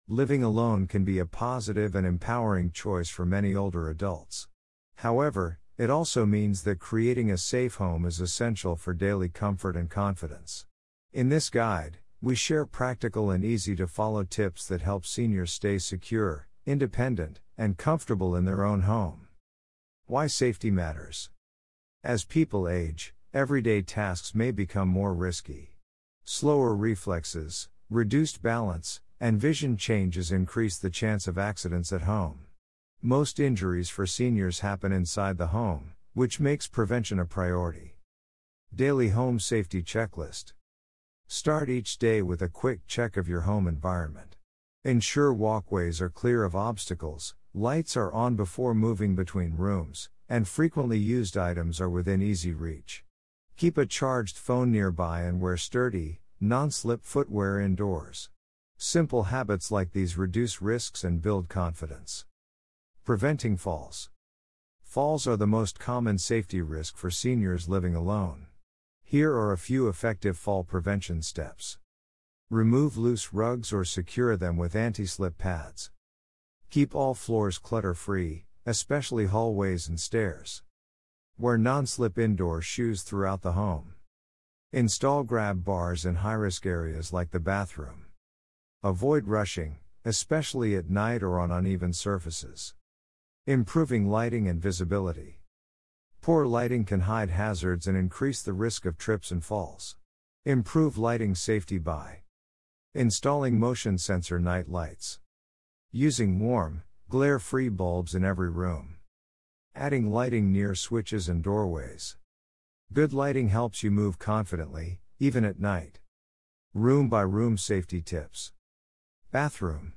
Click play below for a calm narration of these safety tips for seniors.